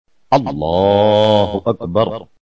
takbeer.mp3